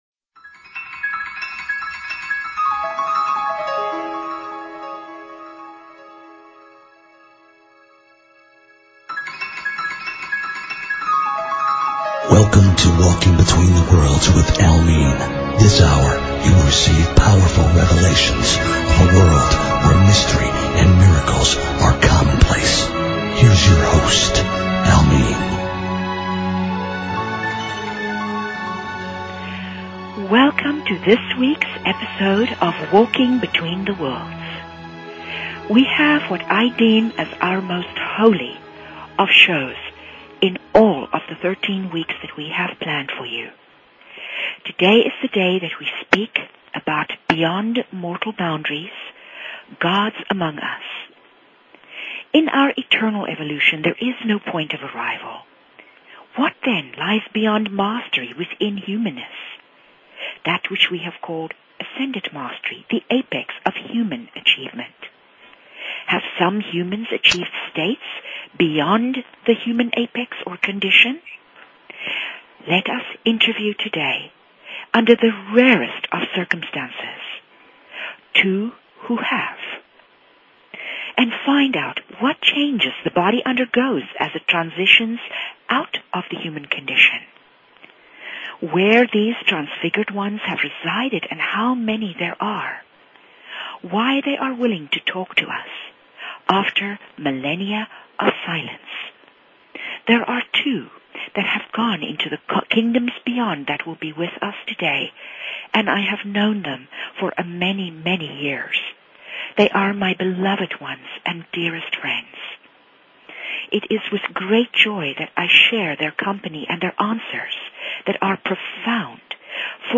Talk Show Episode, Audio Podcast, Secrets_of_the_Hidden_Realms and Courtesy of BBS Radio on , show guests , about , categorized as
These interviews are powerful enough to change your life!